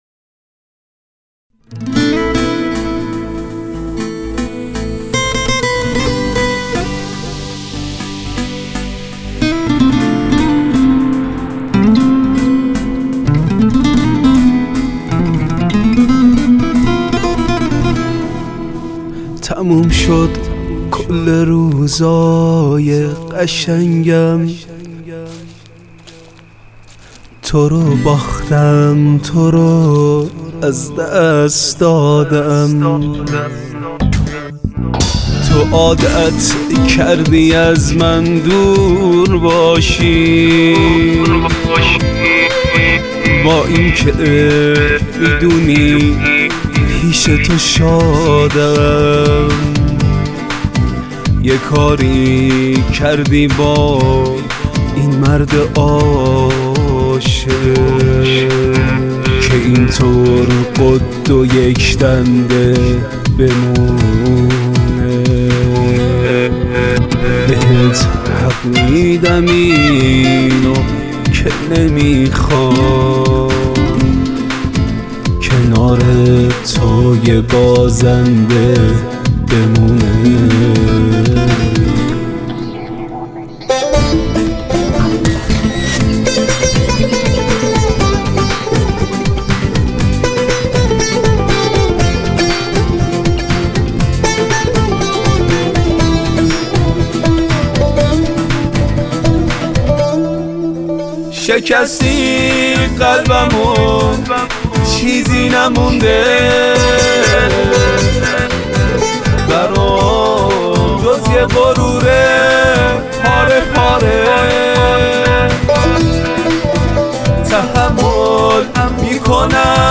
ویالن
گیتار